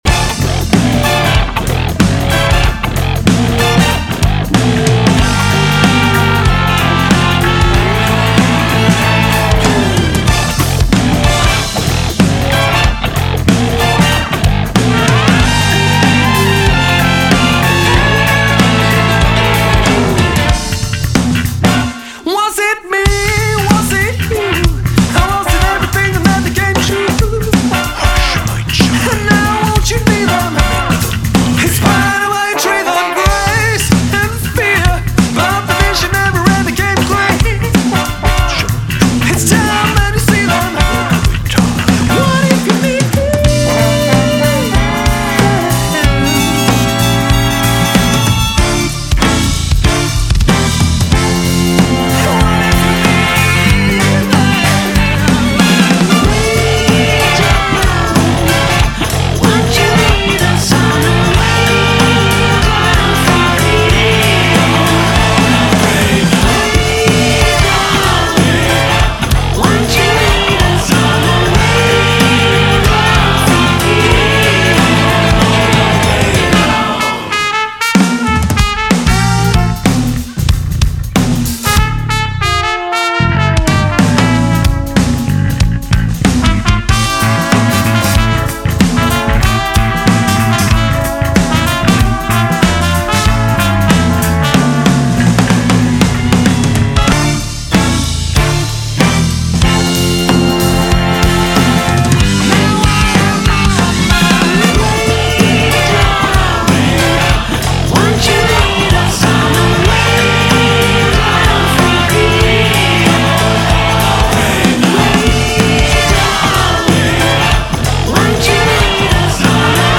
BPM94
Audio QualityLine Out